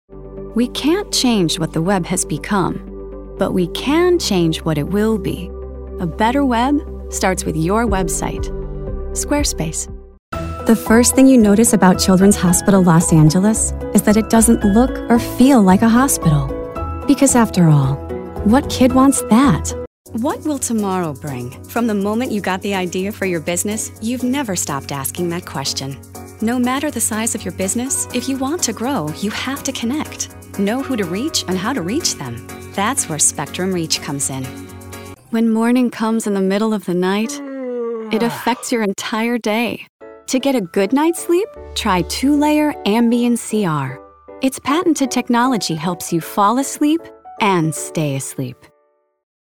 Young Adult, Adult
british rp | natural
southern us | natural
standard us | natural
COMMERCIAL 💸